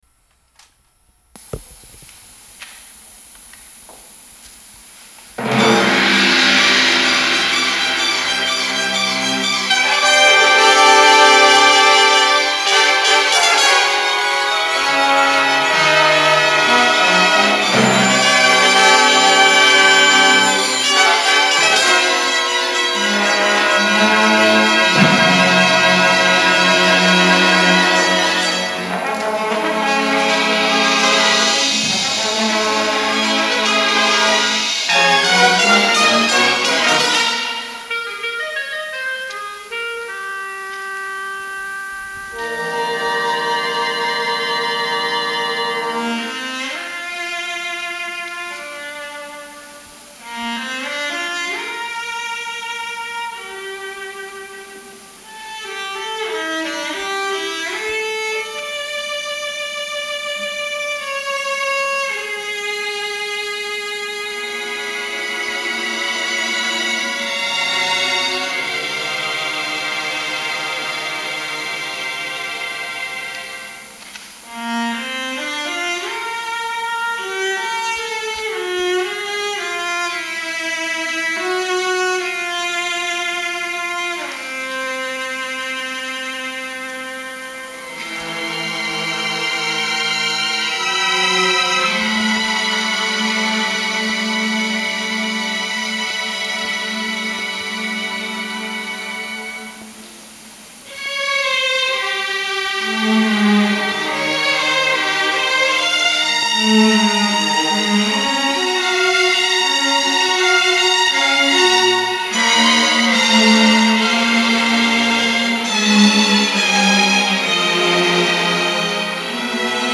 Catharsis for Orchestra - MP3